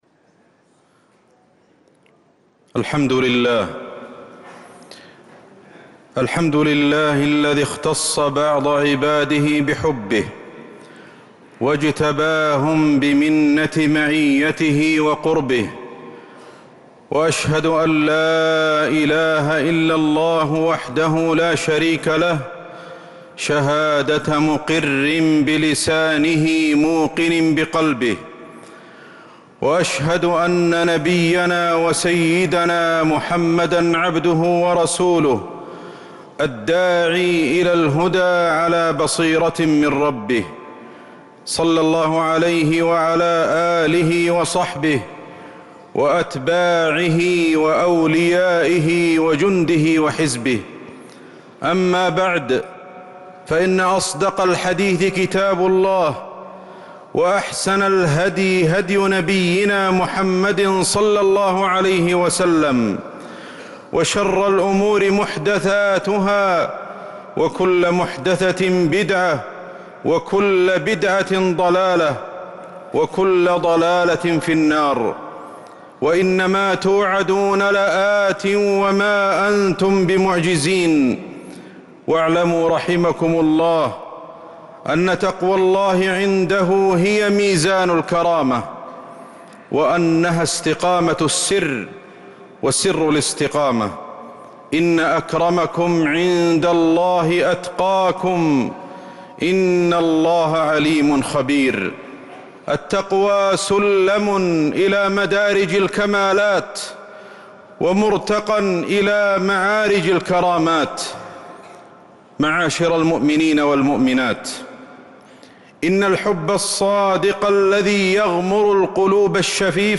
خطبة الجمعة 3-7-1446هـ | Khutbah Jumu’ah 3-1-2025 > الخطب > المزيد - تلاوات الشيخ أحمد الحذيفي